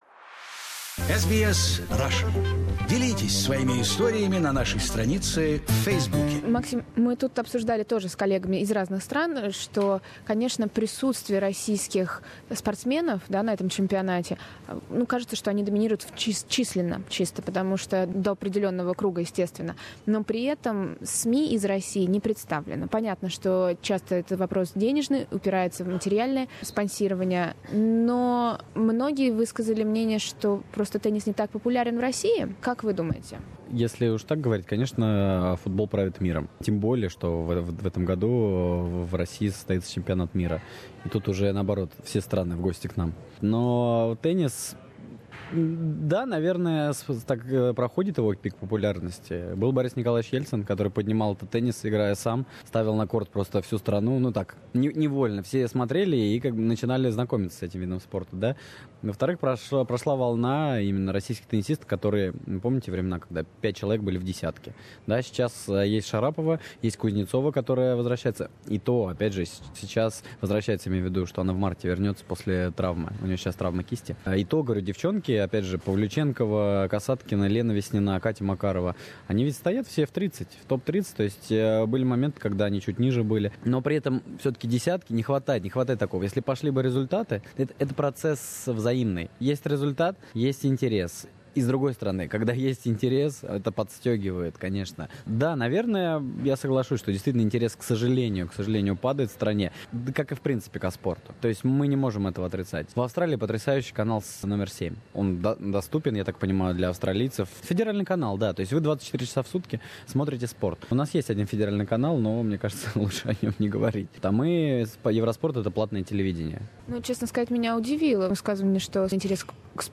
Это вторая часть интервью